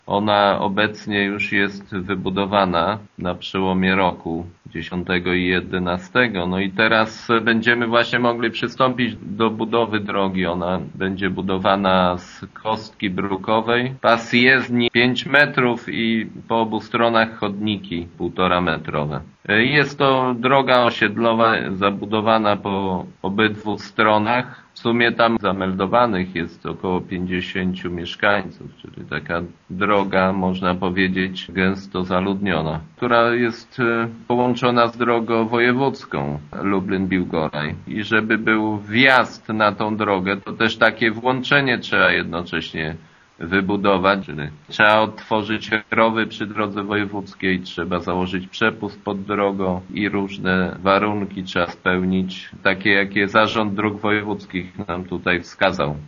Wójt Jacek Anasiewicz przyznaje, że pełną dokumentację tej inwestycji samorząd przygotował już kilka lat temu, ale budowy nie mógł rozpocząć, ponieważ w drodze nie było kanalizacji sanitarnej: